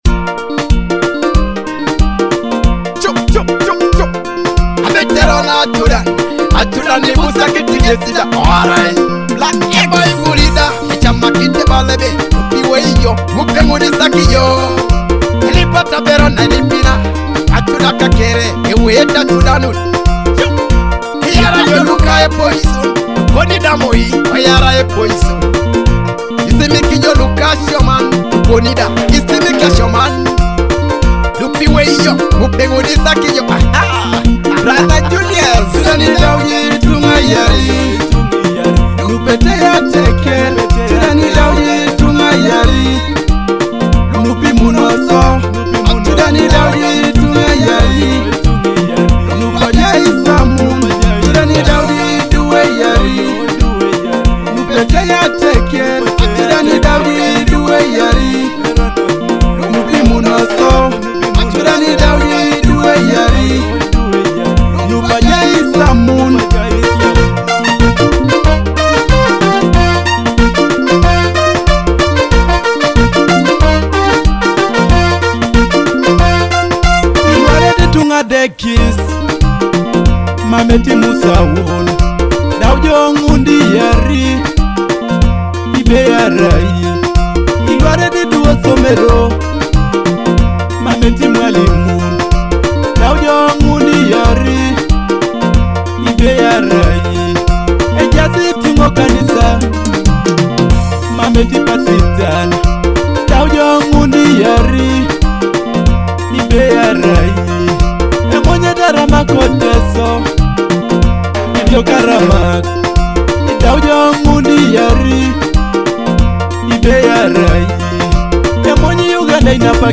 a fusion of folklore and modern sound.